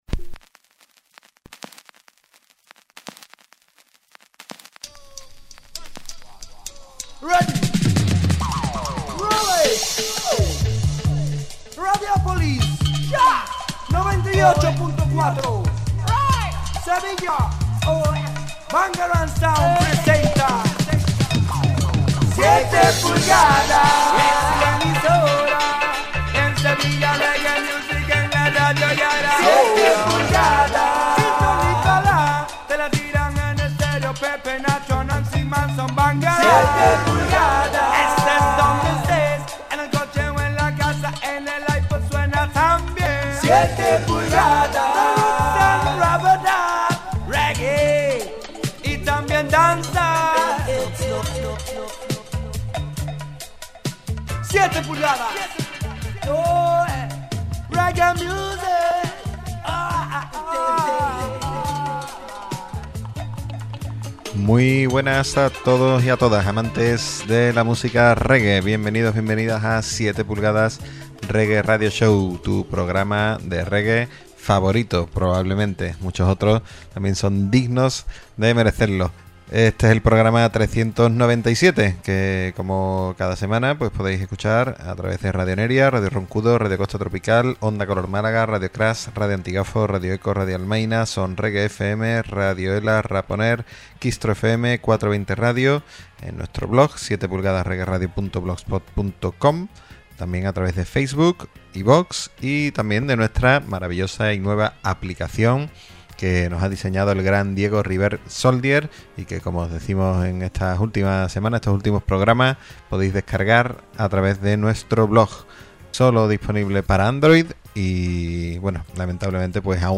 Presentado y dirigido por la Bangarang Sound y grabado en Black Arggh Studio.